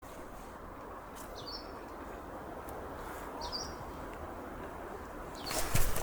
Putni -> Ķauķi -> 4
Hjūma ķauķītis, Phylloscopus humei